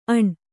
♪ aṇ